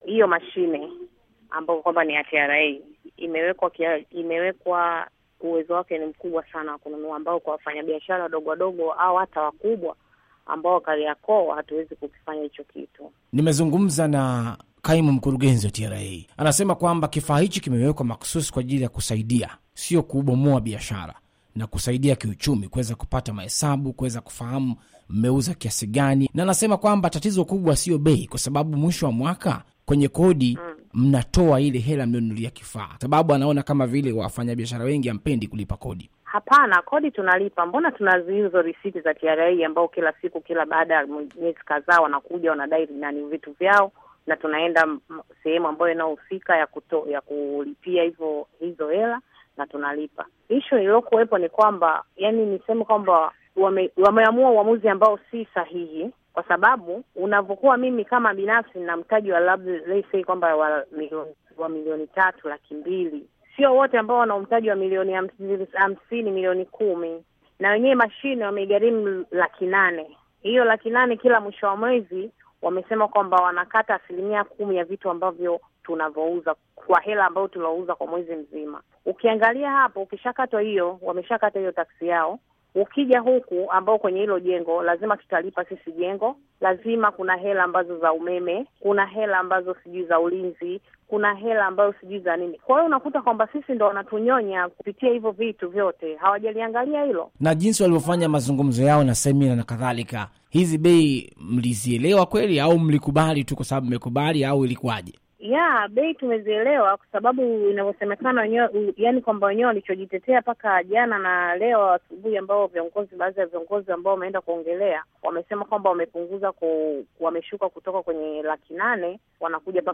Mahojiano na mfanya biashara.